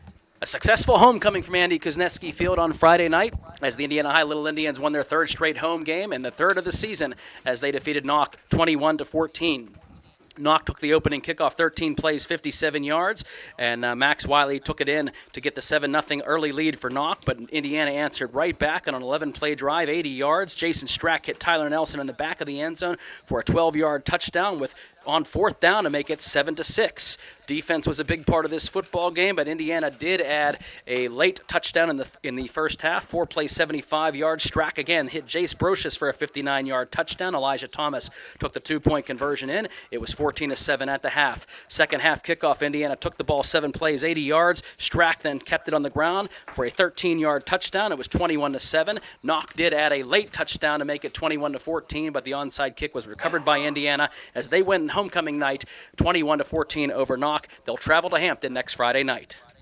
recap